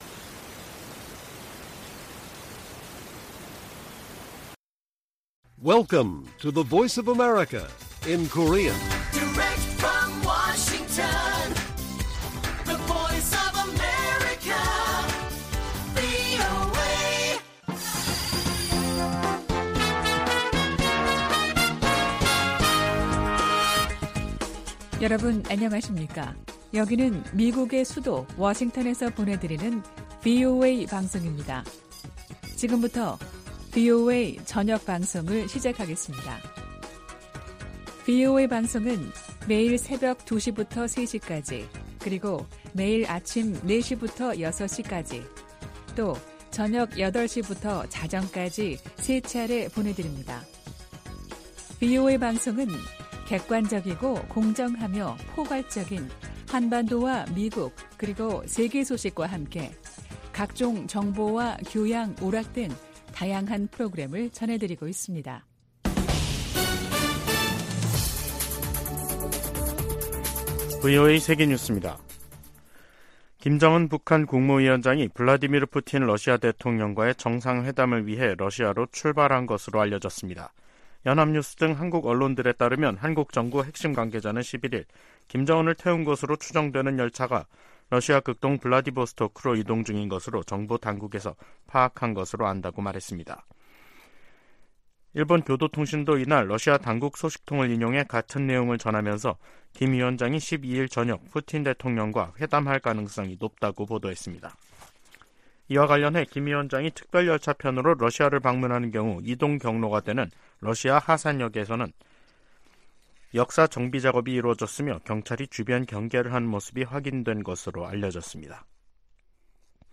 VOA 한국어 간판 뉴스 프로그램 '뉴스 투데이', 2023년 9월 11일 1부 방송입니다. 김정은 북한 국무위원장이 탑승한 것으로 보이는 열차가 러시아로 출발한 것으로 한국 언론들이 보도했습니다. 미국은 러시아와 북한간 무기 거래 저지를 위해 다양한 노력을 기울이면서 상황을 계속 주시하고 있다고 백악관 고위 관리가 밝혔습니다.